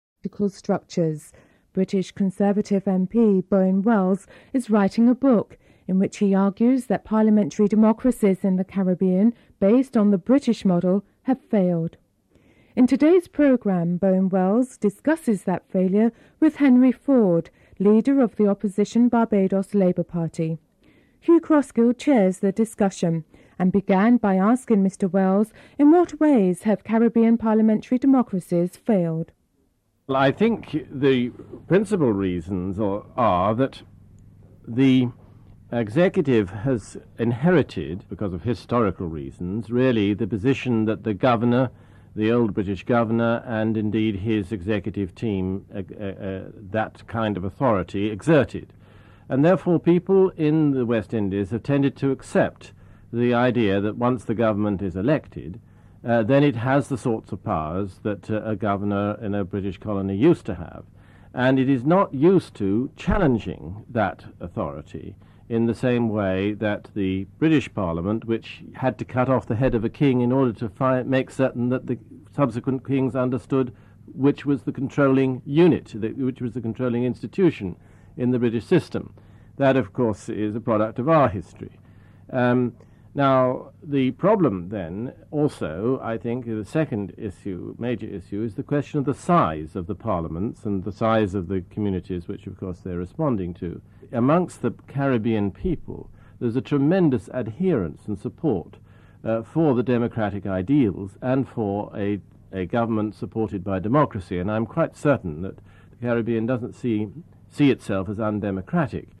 Report commences during the headlines segment.
1. Headlines (00:00-00:29)
British Conservative MP, Bowen Wells, is the author of a book in which he argues that parliamentary democracies in the Caribbean, based on the British model, have failed. Bowen Well discusses this failure with Henry Ford, leader of the Opposition Barbados Labour Party.